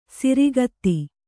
♪ sirigatti